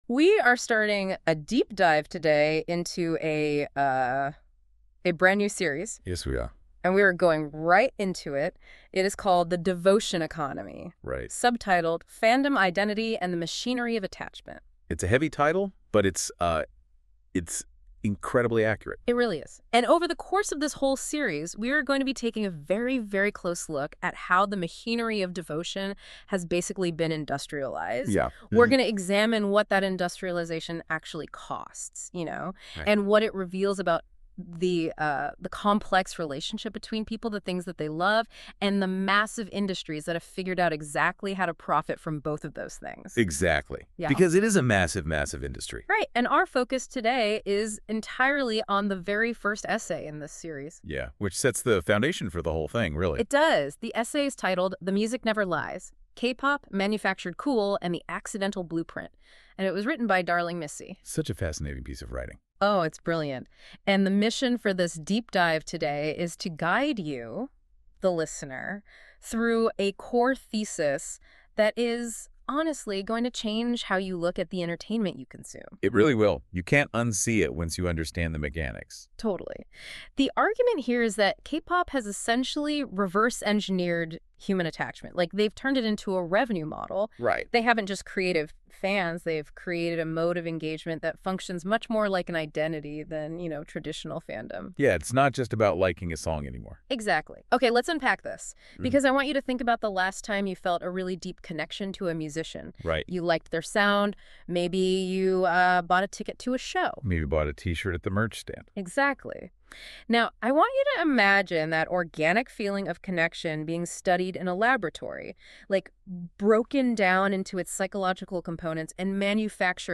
Note : The podcast is generated from the following AI curated text.